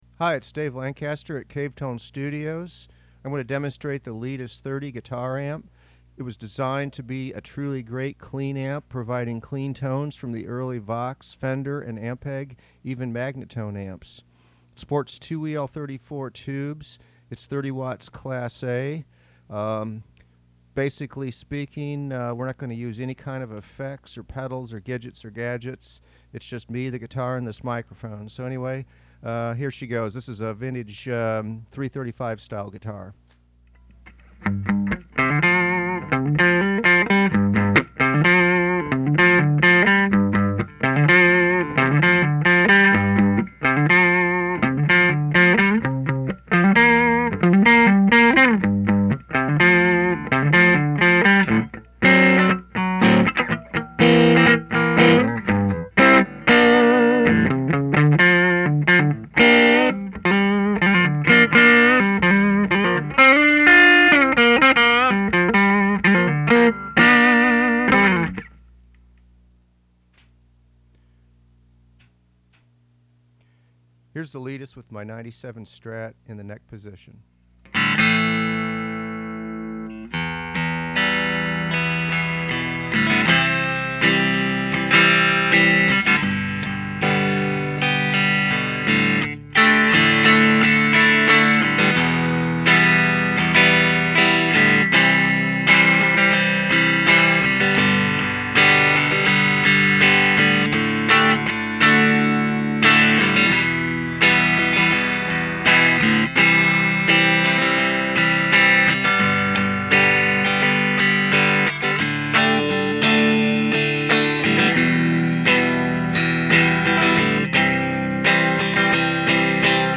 Leedis 30 Tube Guitar Amplifier
The sounds it makes: The Leedis was created as the ultimate clean amplifier with great headroom and amazing bloom on the notes.
What this creates is a wide range of creamy and jangly tones.